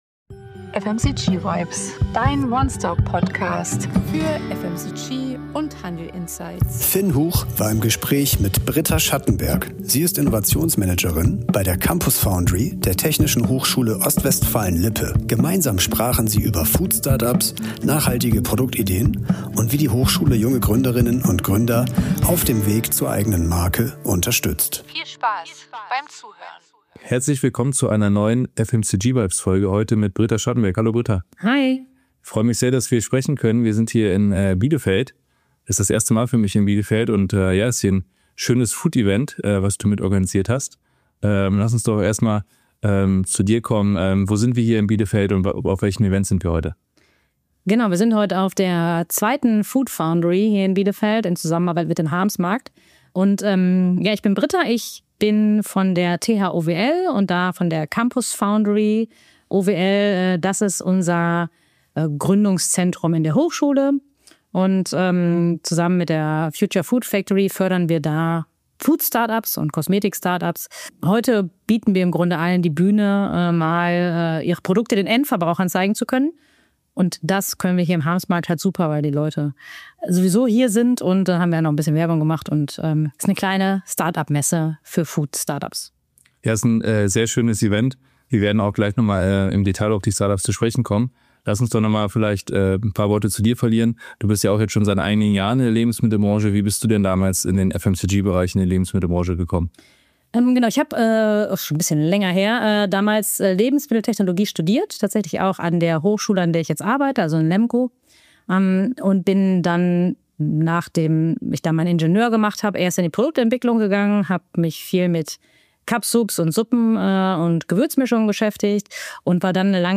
Expertinnentalk